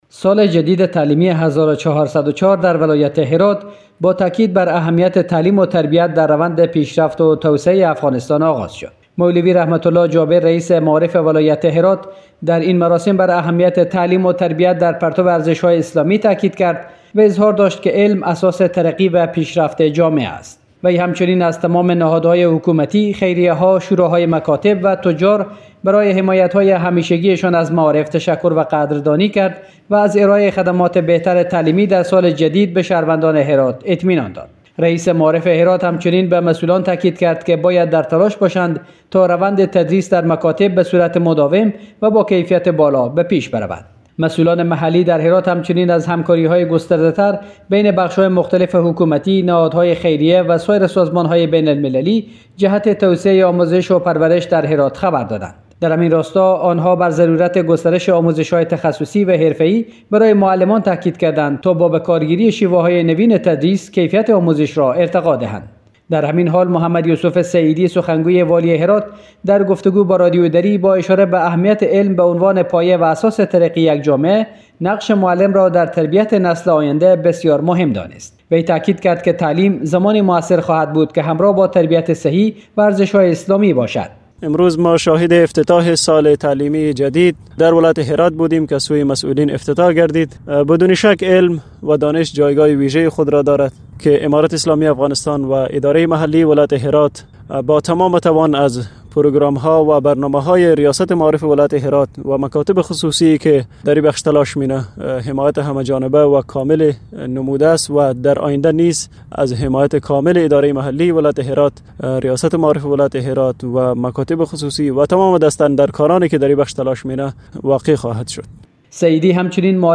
آغاز سال تعلیمی جدید در مناطق گرمسیر افغانستان کلیدواژه گزارش فرهنگی هرات وزارت معارف افغانستان بازگشایی مدارس